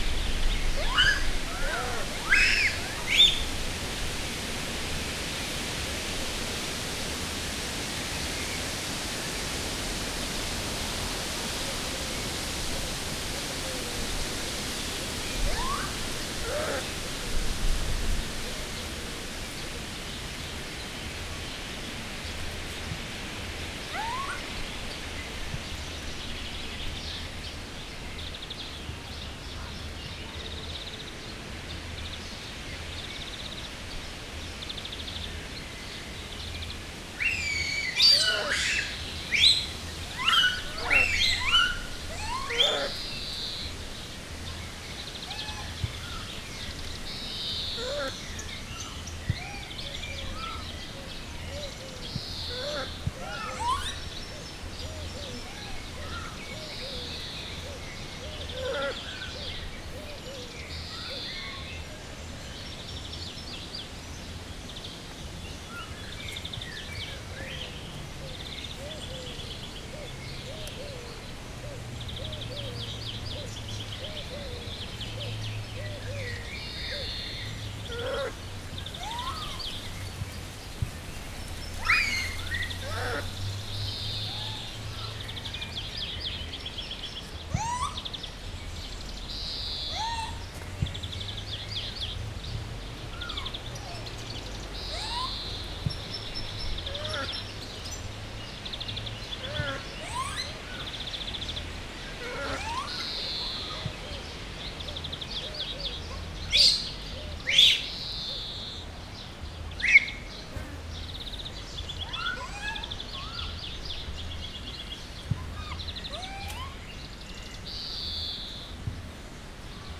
Directory Listing of /_MP3/allathangok/jaszberenyizoo2015_professzionalis/japanmakako/
beszedesek_futtyognek_hatulrolfujoszel_c02.19.WAV